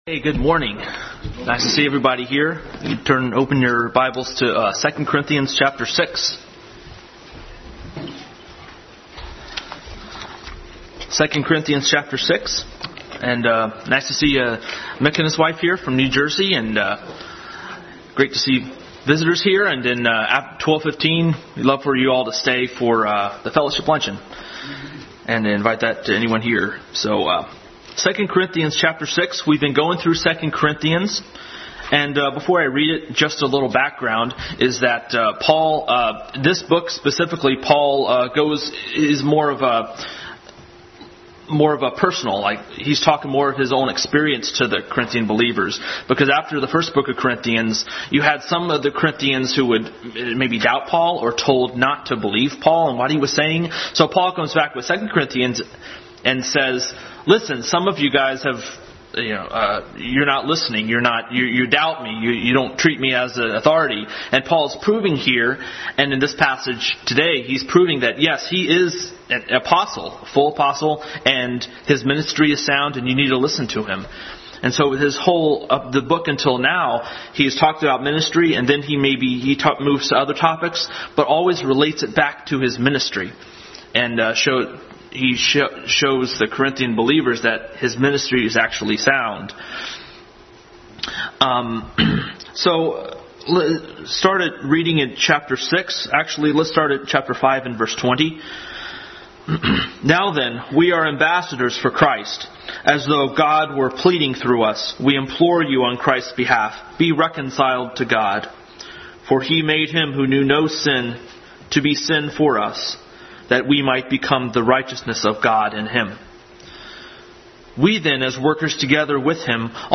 Adult Sunday School Class continued study in 2 Corinthians.
Service Type: Sunday School